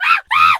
pgs/Assets/Audio/Animal_Impersonations/monkey_2_scream_03.wav at master
monkey_2_scream_03.wav